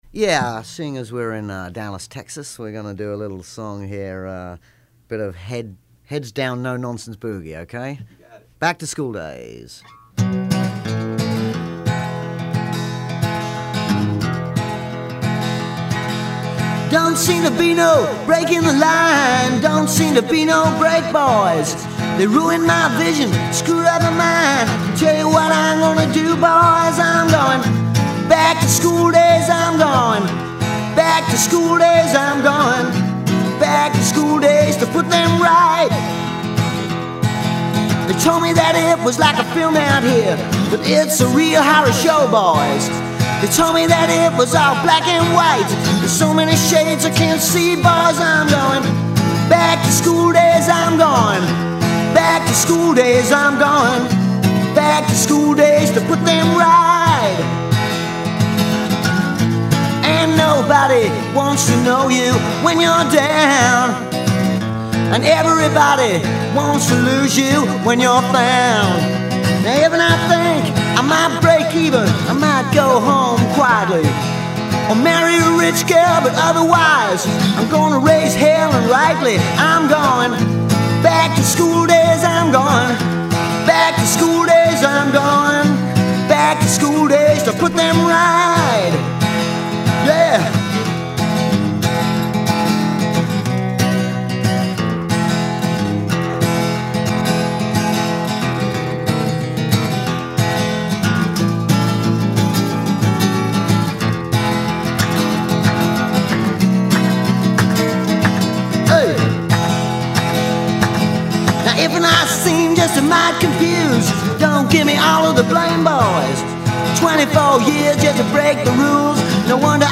acoustic guitar